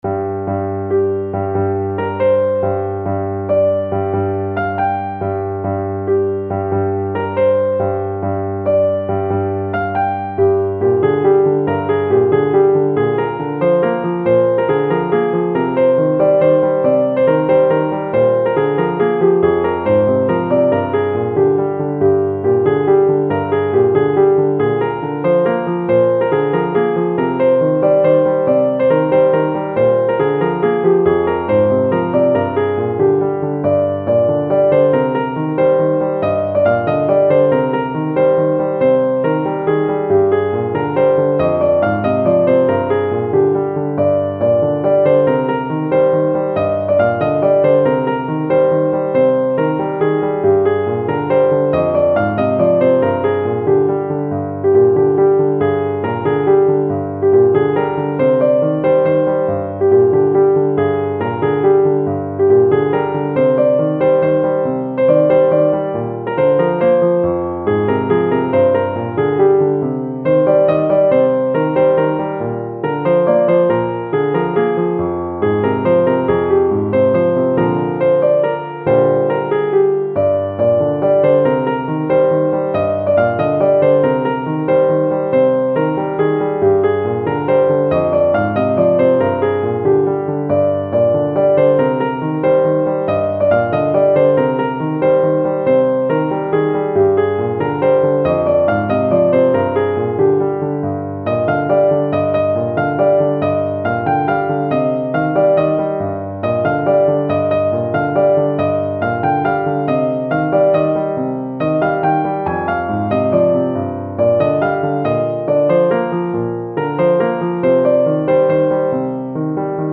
نت پیانو
• سطح نت : متوسط